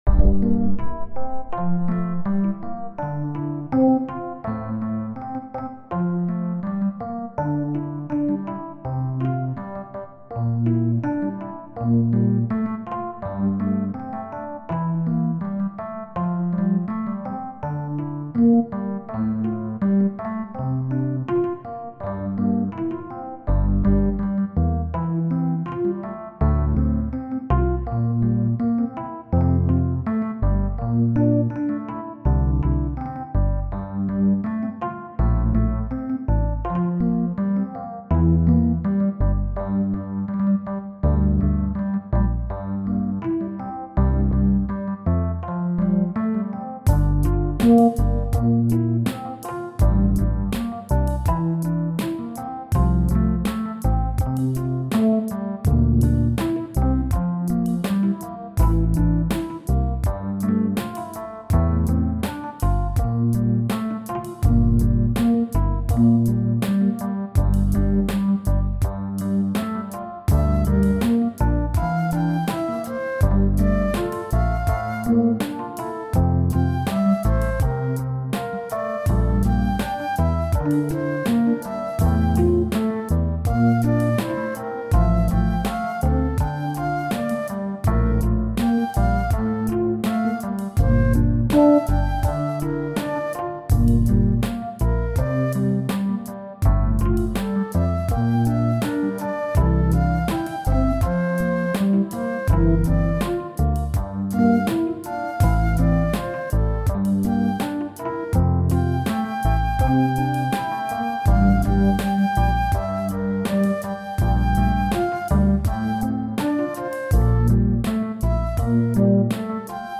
Brumenn est un duo piano électrique/flute alors que nijal est un morceau ambient/New age.
Henon ( 1.8 - 0.5) Sol (G) Dorien 82